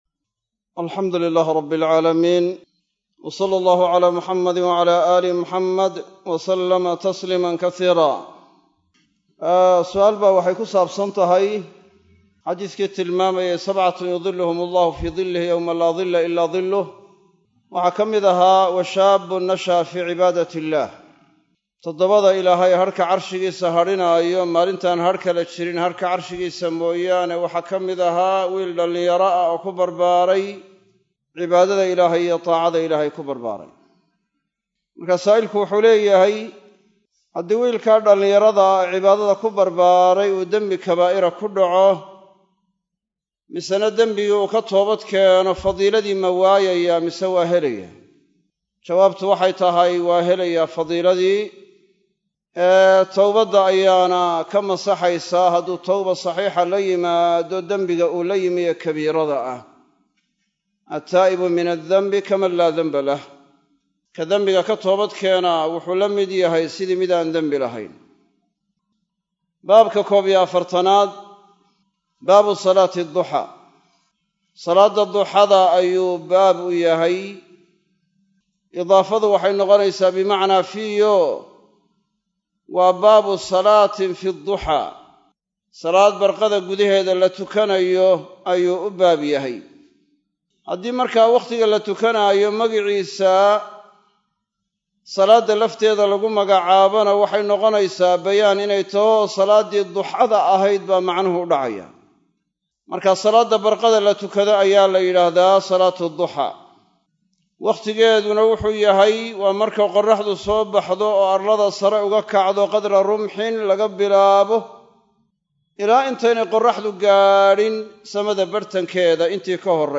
Masjid Af-Gooye – Burco